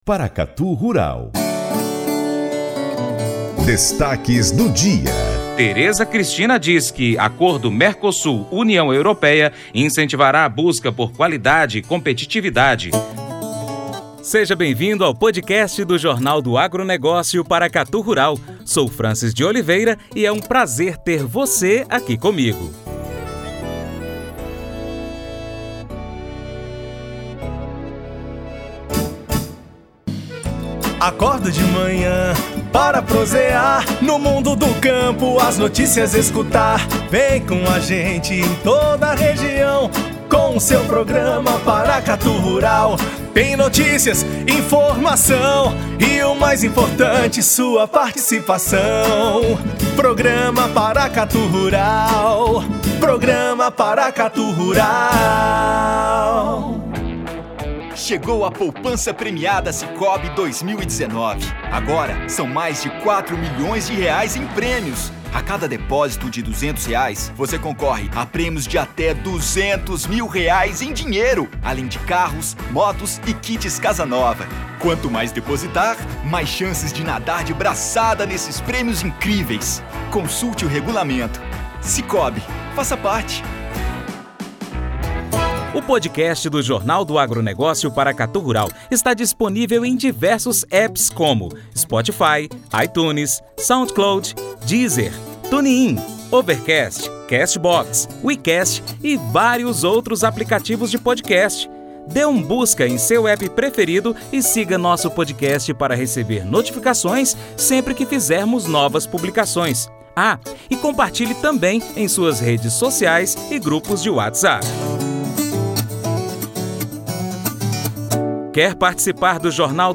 Em entrevista à imprensa, ela afirmou que o acordo irá permitir que os produtos brasileiros se tornem mais atraentes e alcancem um mercado de aproximadamente 700 milhões de pessoas.